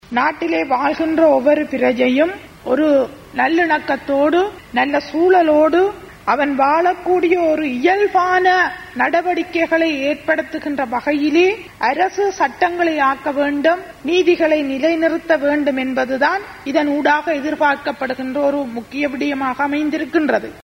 இதேவேளை, இந்த விவாதத்தில் கலந்து கொண்டு உரையாற்றிய நாடாளுமன்ற உறுப்பினர் சாந்தி ஸ்ரீஸ்கந்தராஜா இவ்வாறு குறிப்பிட்டார்.
குரல் சாந்தி